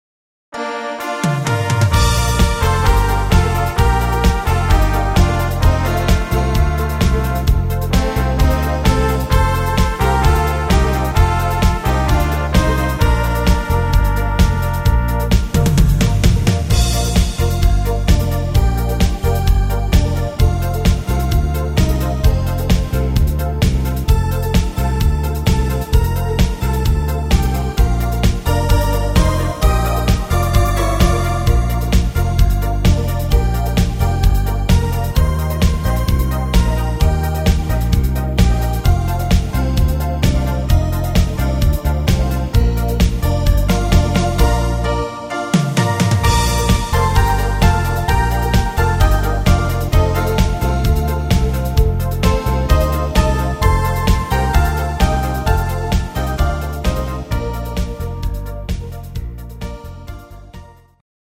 Rhythmus  Discofox
Art  Deutsch, Volkstümlich